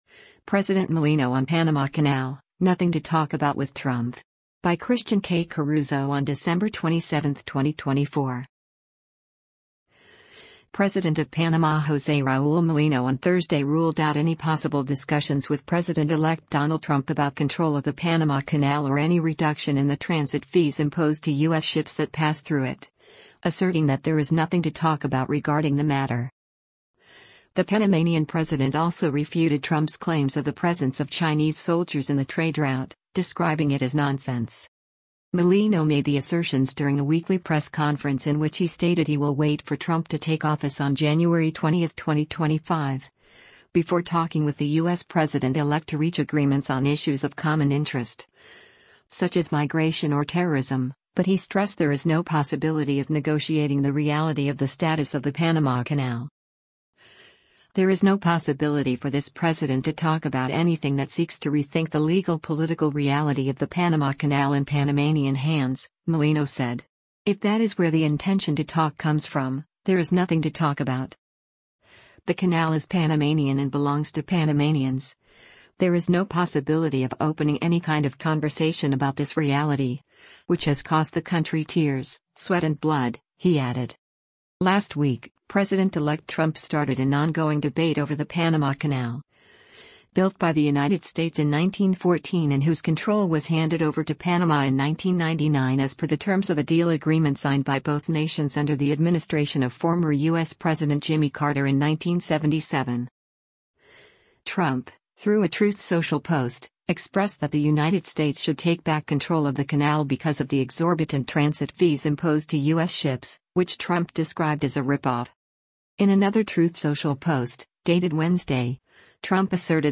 Mulino made the assertions during a weekly press conference in which he stated he will wait for Trump to take office on January 20, 2025, before talking with the U.S. president-elect to reach agreements on issues of common interest, such as migration or terrorism — but he stressed there is no possibility of negotiating the “reality” of the status of the Panama Canal: